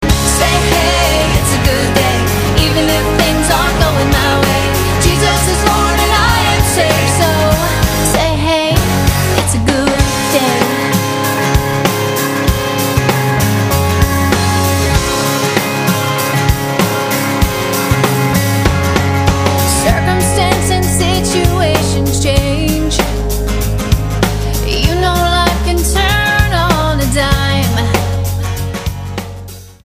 STYLE: Pop
acoustic-driven pop and rock. There's catchy pop songs